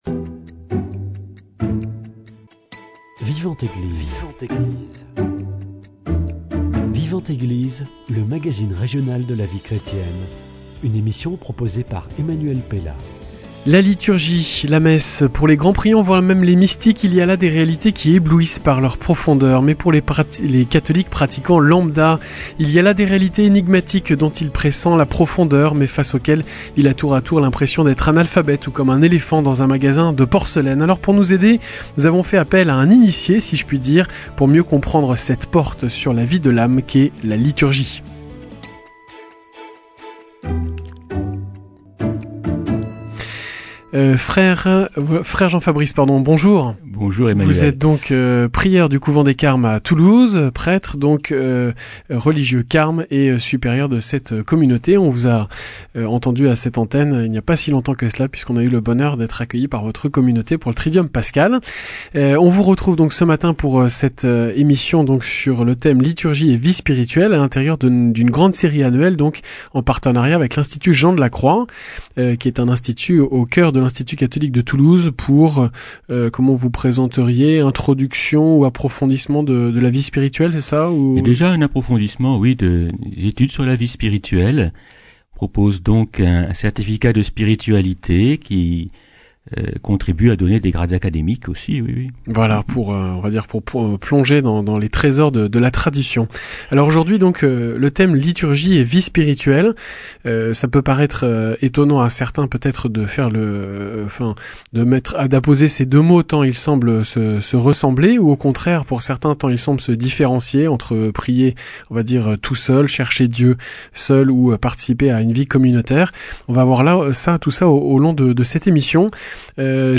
La porte d’entrée de la vie spirituelle c’est la liturgie. Telle est la conviction de mon invité de ce matin.